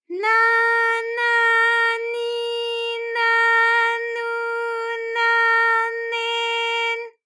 ALYS-DB-001-JPN - First Japanese UTAU vocal library of ALYS.
na_na_ni_na_nu_na_ne_n.wav